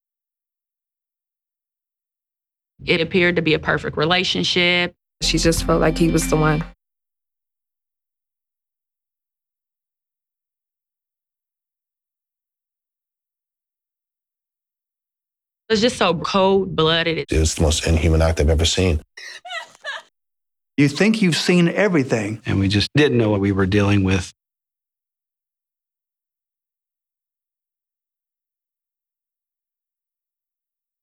FA Marathon_Starting Next Monday30_SOT.wav